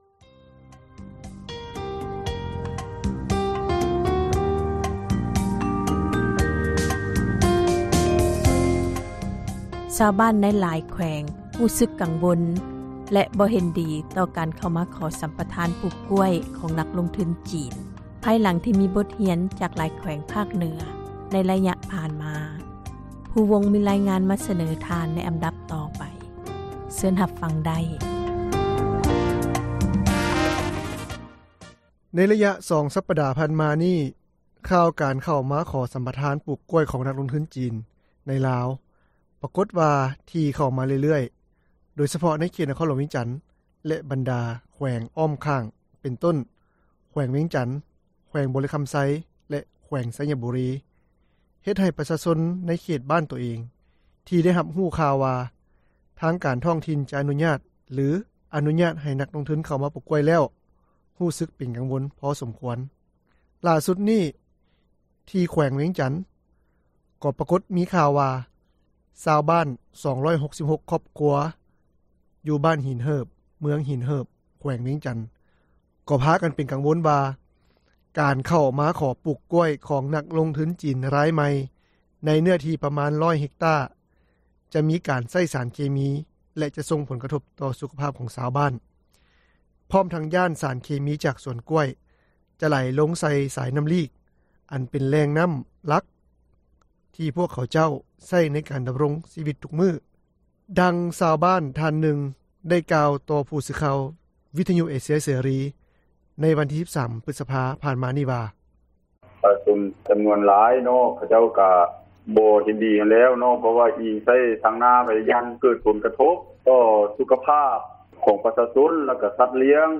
ຫຼ້າສຸດນີ້, ທີ່ແຂວງວຽງຈັນ ກໍປະກົດມີຂ່າວວ່າ ຊາວບ້ານ 266 ຄອບຄົວຢູ່ບ້ານຫີນເຫີບ, ເມືອງຫີນເຫີບ ແຂວງວຽງຈັນ ກໍພາກັນກັງວົນວ່າ ການເຂົ້າມາຂໍປູກກ້ວຍ ຂອງນັກລົງທຶນຈີນ ຣາຍໃໝ່ ໃນເນື້ອທີ່ ປະມານ 100 ເຮັກຕ້າ ຈະມີການໃຊ້ສານເຄມີ ແລະຈະສົ່ງ ຜົນກະທົບຕໍ່ ສຸຂພາບຂອງຊາວບ້ານ ພ້ອມທັງຢ້ານສານເຄມີຈາກສວນກ້ວຍ ຈະໄຫຼລົງໃສ່ສາຍນໍ້າລີກ ອັນເປັນແຫຼ່ງນໍ້າຫຼັກ ທີ່ພວກເຂົາເຈົ້າ ໃຊ້ໃນການ ດໍາຣົງຊີວິຕທຸກມື້, ດັ່ງຊາວບ້ານທ່ານນຶ່ງ ໄດ້ກ່າວຕໍ່ຜູ້ສື່ຂ່າວ ວິທຍຸເອເຊັຽເສຣີ ໃນວັນທີ່ 13 ພຶສພາ ຜ່ານມານີ້ວ່າ: